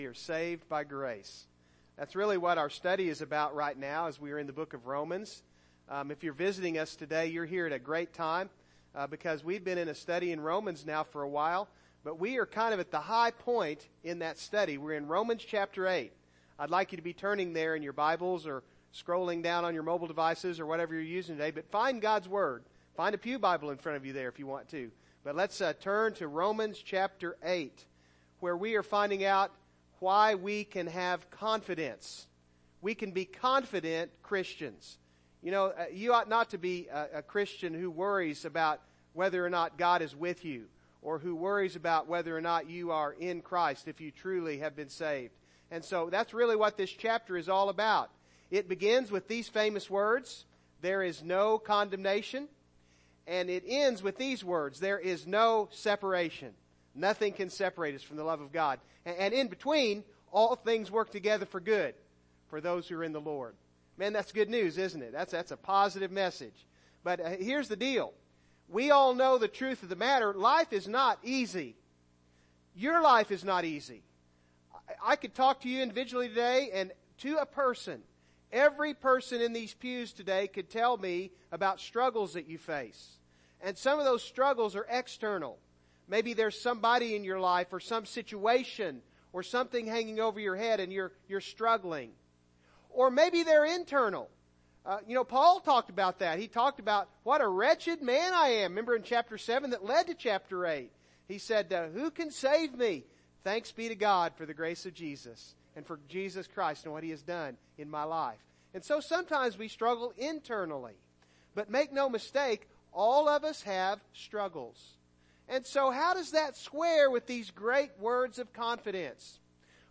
Romans 8:18-30 Service Type: Morning Service Download Files Notes « Give Me This Mountain!